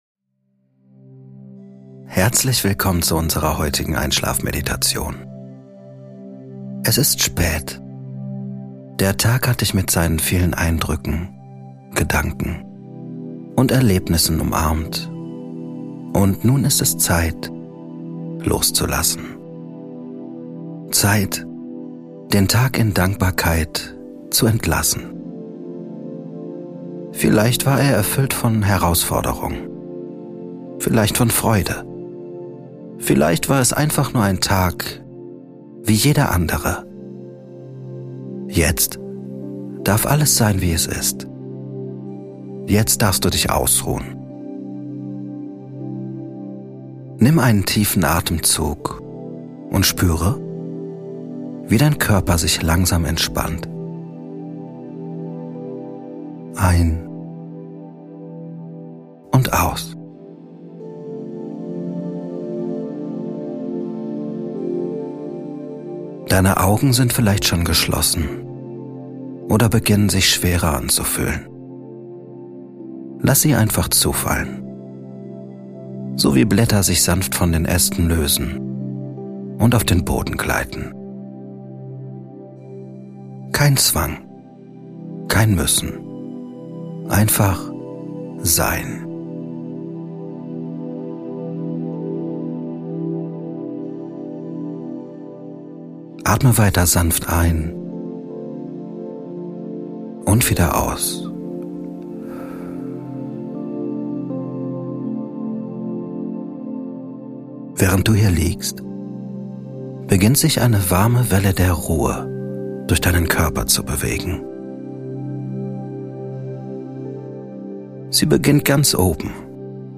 Einschlafmeditation - Eine geführte Reise in die Stille der Nacht ~ Kopfkanal - Geführte Meditationen Podcast